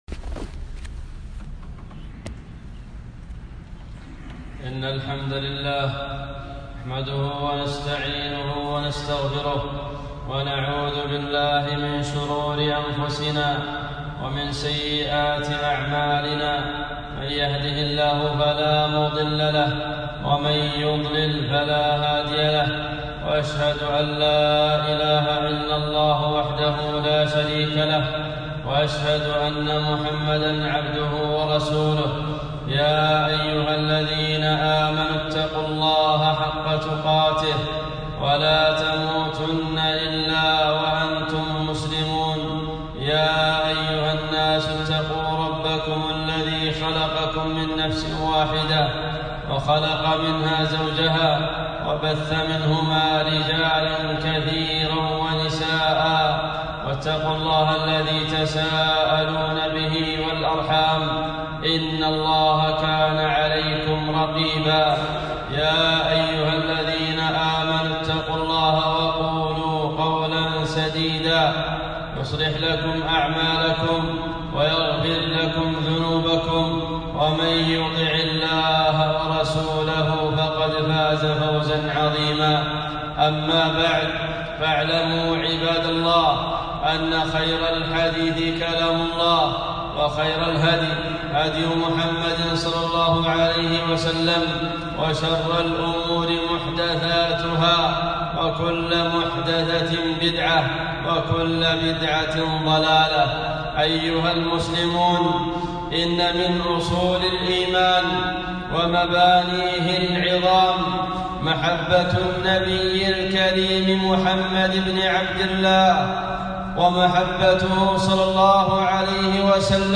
خطبة - استوصوا بالنساء خيرا